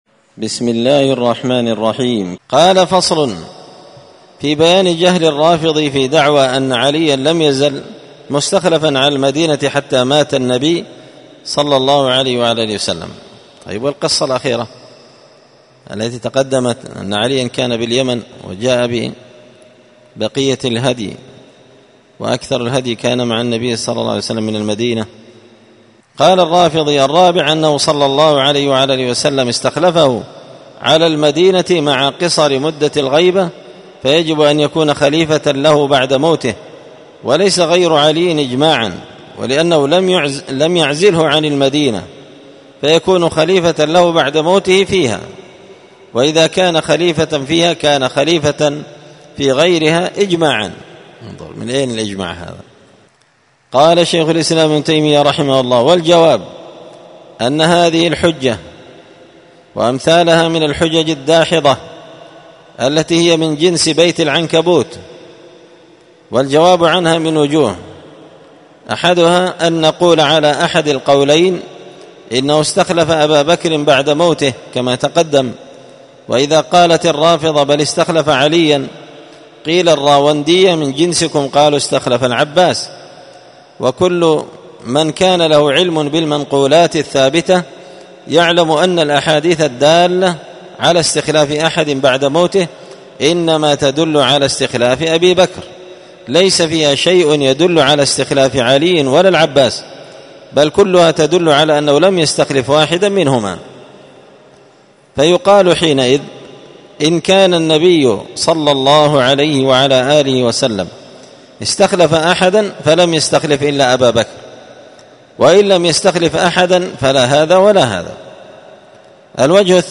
الأربعاء 21 صفر 1445 هــــ | الدروس، دروس الردود، مختصر منهاج السنة النبوية لشيخ الإسلام ابن تيمية | شارك بتعليقك | 97 المشاهدات
مسجد الفرقان قشن_المهرة_اليمن